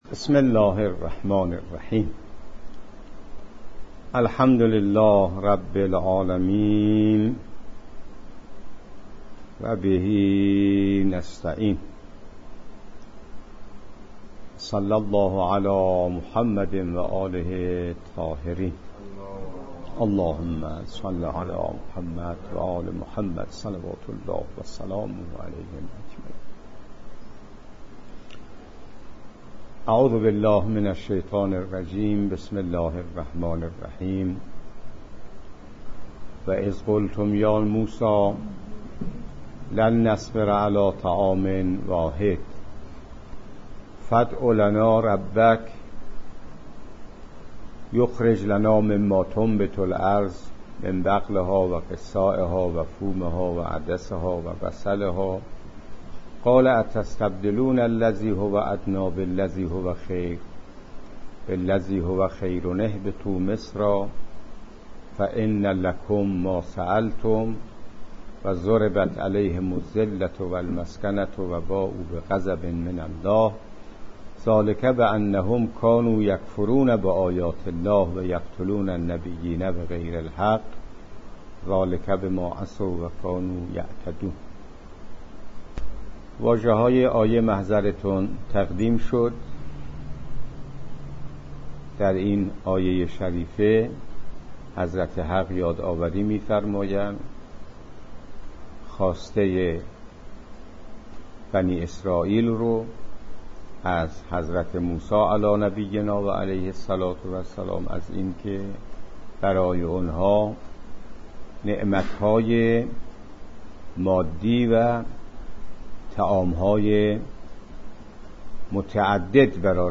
جلسه درس تفسیر قرآن کریم